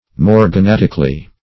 --Brande & C. -- Mor`ga*nat"ic*al*ly, adv.